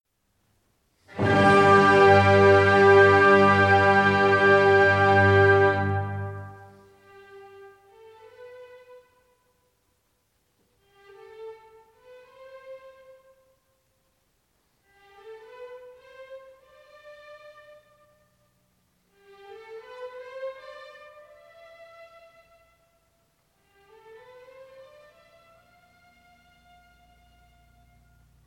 Joyful, playful, energetic!
The introduction of the 4th movement is in adagio.